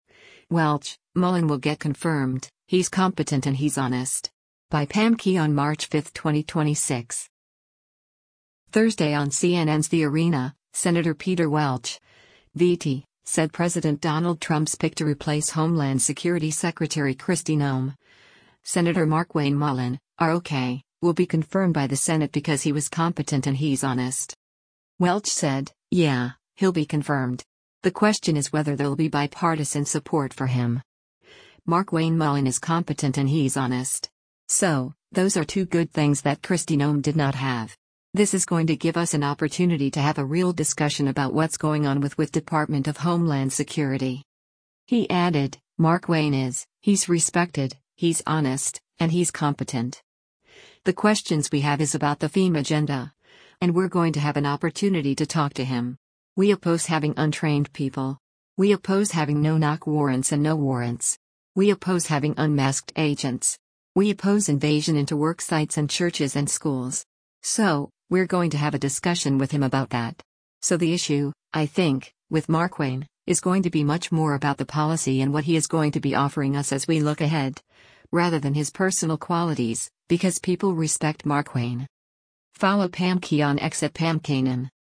Thursday on CNN’s “The Arena,” Sen. Peter Welch (VT) said President Donald Trump’s pick to replace Homeland Security Secretary Kristi Noem, Sen. Markwayne Mullin (R-OK), will be confirmed by the Senate because he was “competent and he’s honest.”